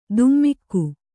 ♪ dummikku